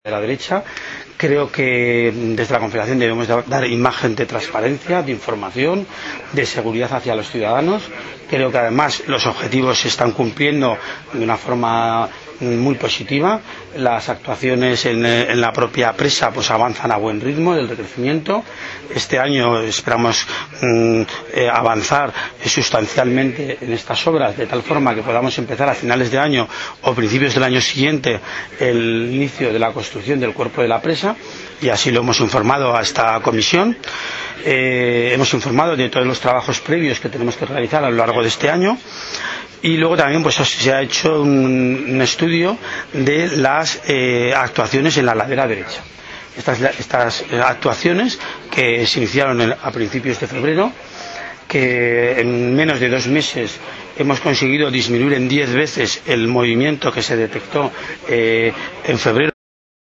Declaraciones_presidente_CHE_-Visita_obras_recrecimiento_Yesa.mp3